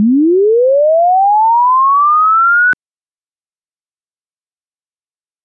spk_cal_sweep.wav